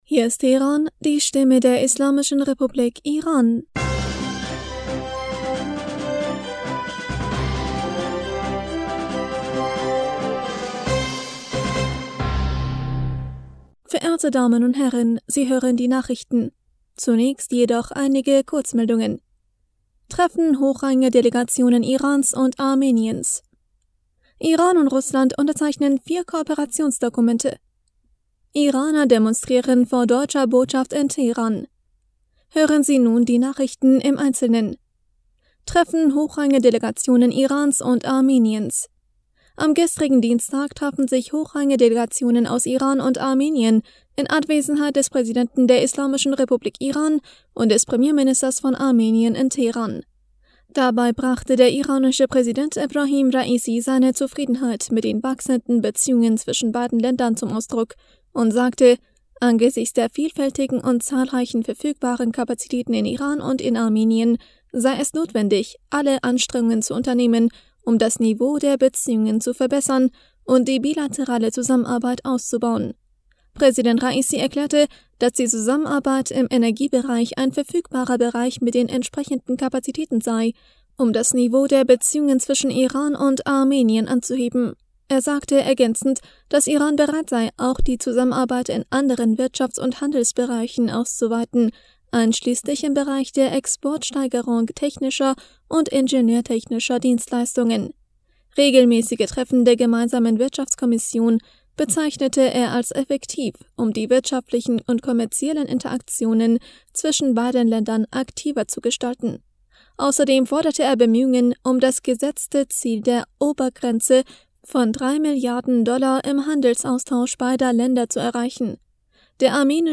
Nachrichten vom 2. November 2022